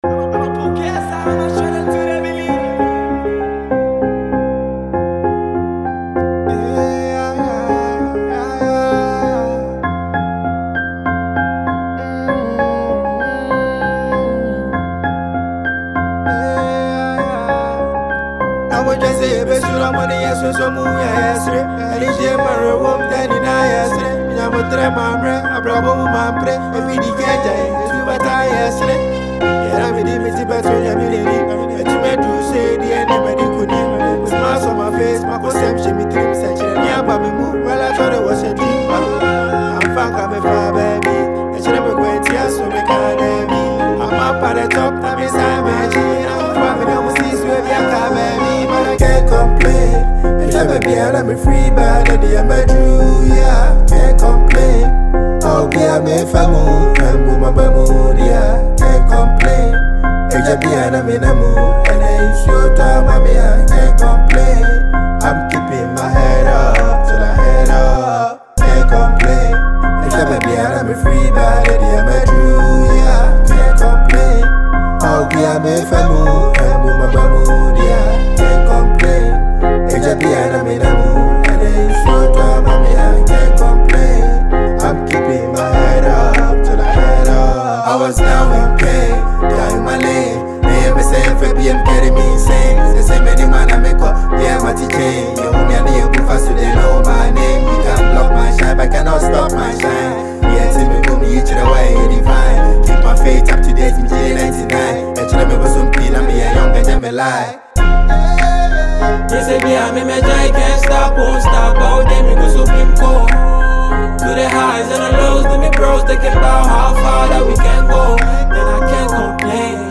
a Ghanaian asakaa artist and rapper